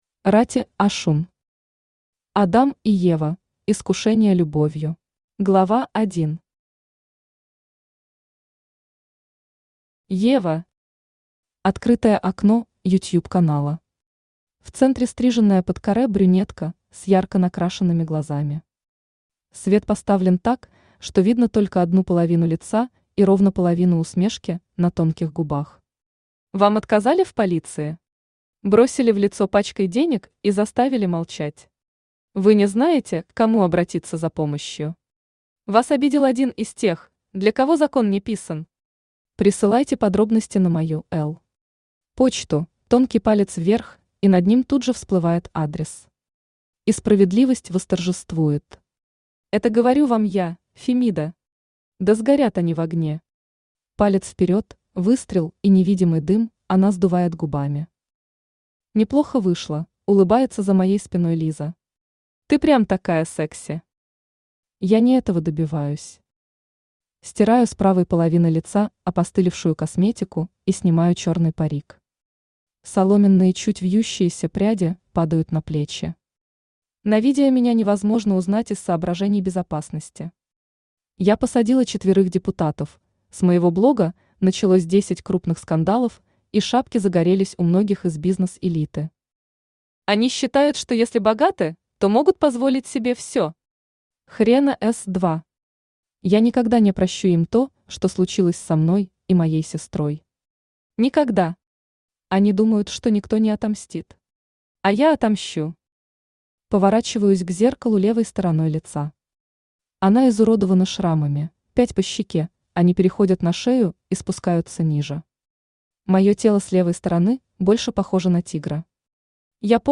Аудиокнига Адам и Ева: искушение любовью | Библиотека аудиокниг
Aудиокнига Адам и Ева: искушение любовью Автор Рати Ошун Читает аудиокнигу Авточтец ЛитРес.